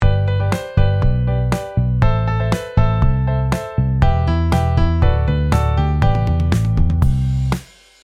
まだ曲の断片でしかありませんが、 Logic Proで、メロディとベース、ドラムを入れて、 mp3ファイルで、書き出したので、そのやり方をのせておきます。
それぞれのバランスとかはまだわからないので、 現在出来上がった状態のままです。 初音ミクも登場しておりません。